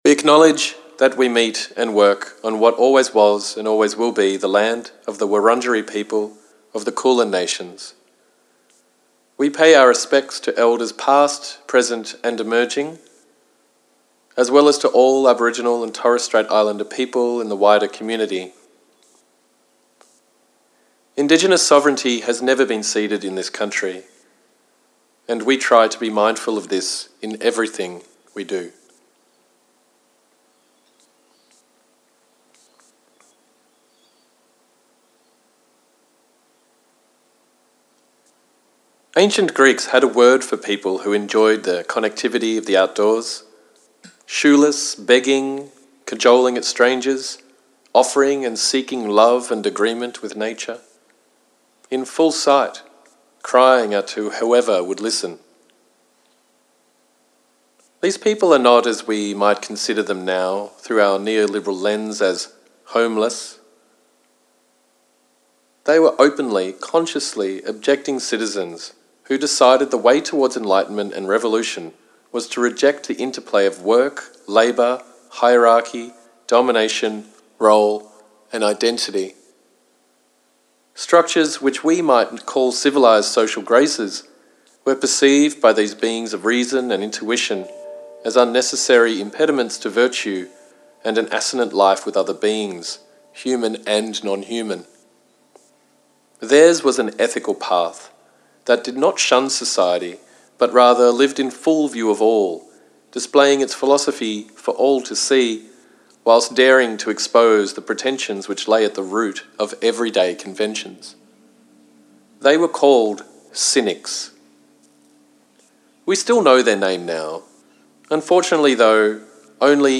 Originally heard by two audiences simultaneously; one group meandering through the Darebin parklands, via headphones, and the other inside a house overlooking the Darebin creek, live with the artist.